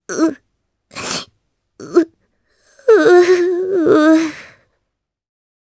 Requina-Vox_Sad_jp.wav